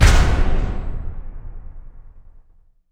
LC IMP SLAM 1C.WAV